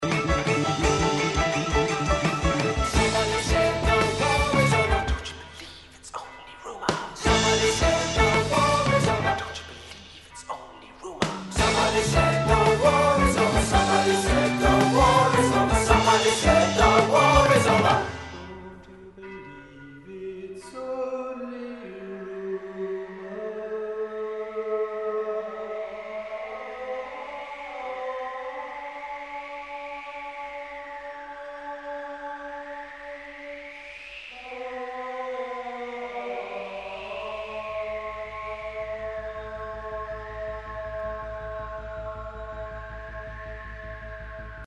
Recorded at Command Studios, London